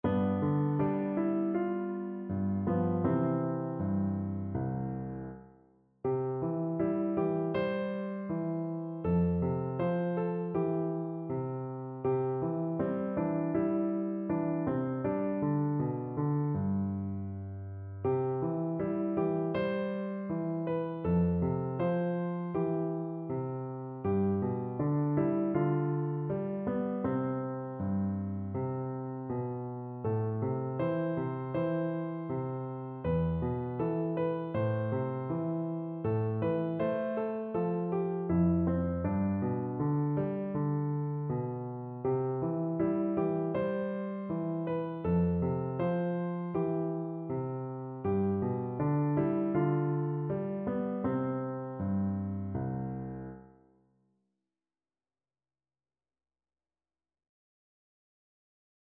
Piano version
4/4 (View more 4/4 Music)
Andante
Piano  (View more Easy Piano Music)
Classical (View more Classical Piano Music)